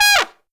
Index of /90_sSampleCDs/Best Service ProSamples vol.25 - Pop & Funk Brass [AKAI] 1CD/Partition C/TRUMPET FX3